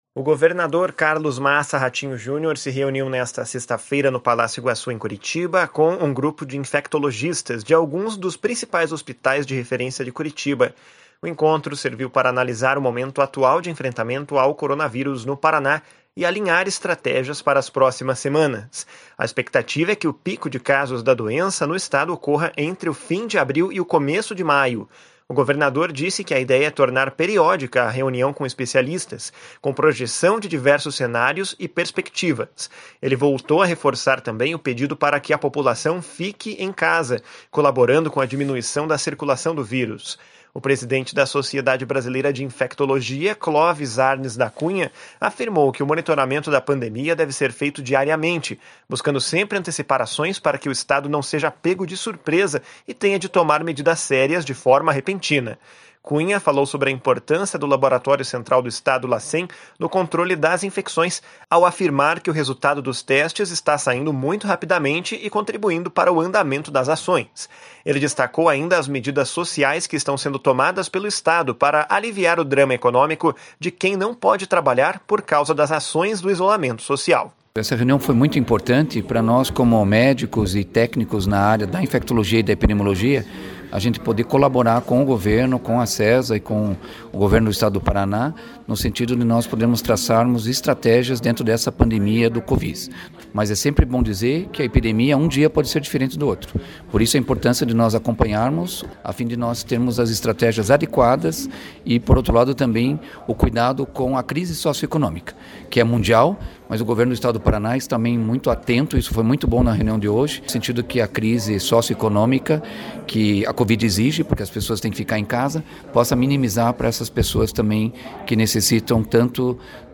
O secretário de Estado da Saúde, Beto Preto, citou a opção do Governo do Estado pela transparência nos números e nas ações que estão sendo tomadas.